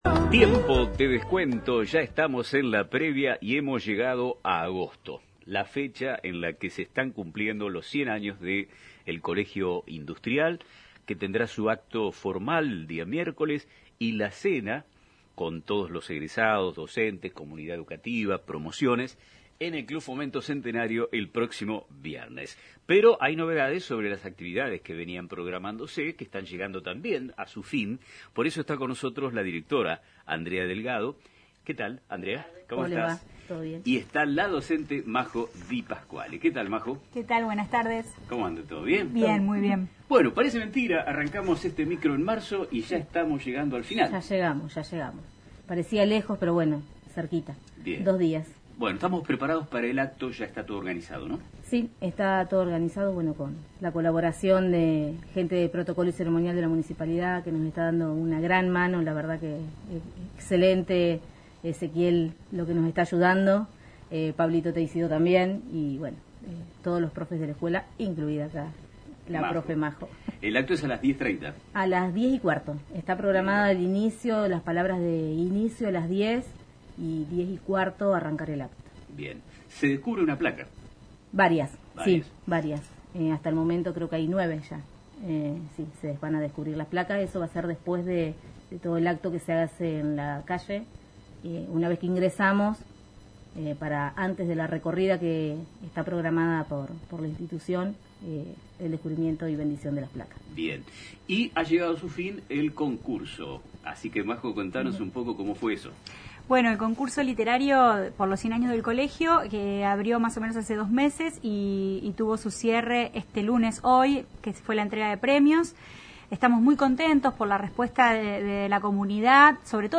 En una reciente entrevista en el programa «Nuestro Tiempo», emitido todas las tardes por Radio Mon Pergamino